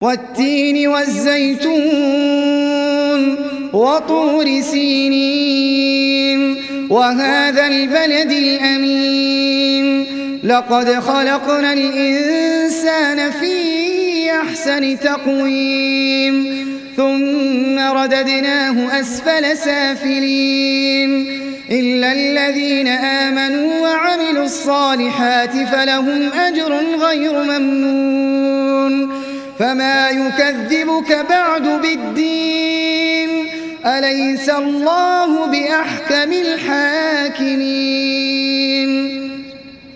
تحميل سورة التين mp3 بصوت أحمد العجمي برواية حفص عن عاصم, تحميل استماع القرآن الكريم على الجوال mp3 كاملا بروابط مباشرة وسريعة